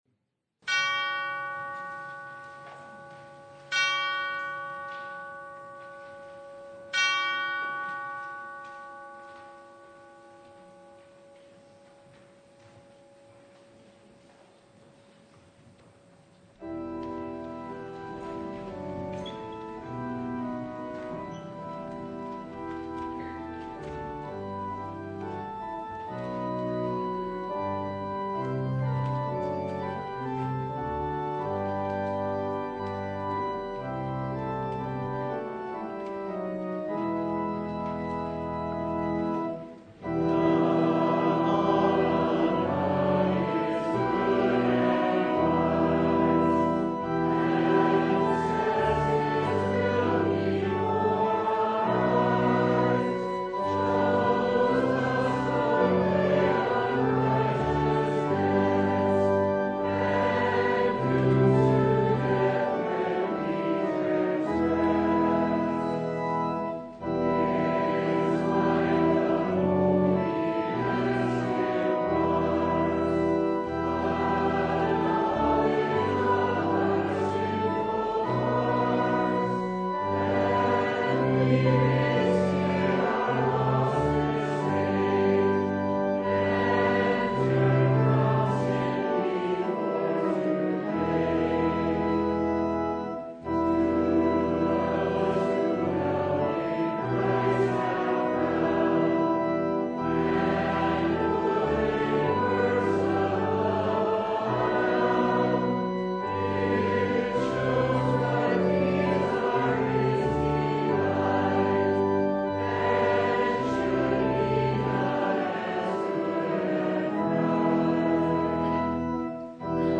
Luke 13:31-35 Service Type: Sunday For a rebellious people